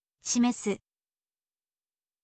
shimesu